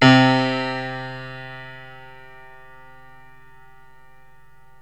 PIANO 0003.wav